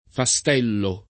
fastello [ fa S t $ llo ]